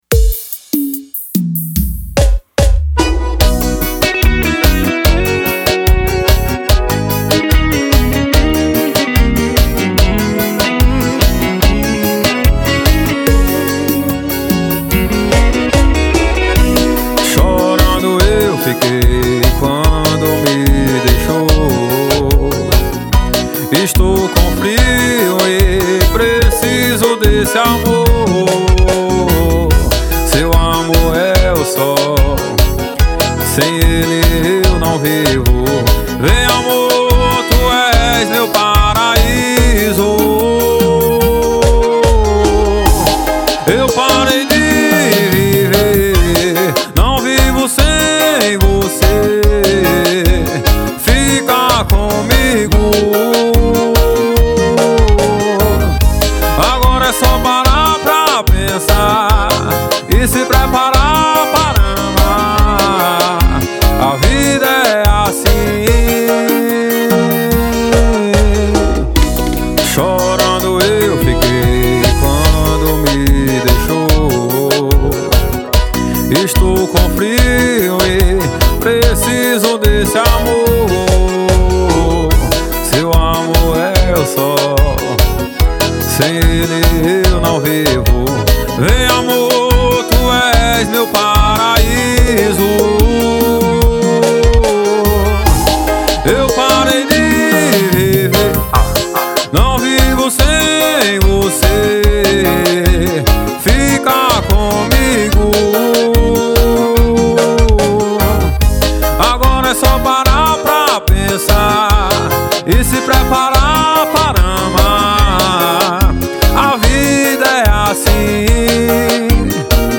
2024-02-14 17:57:24 Gênero: Forró Views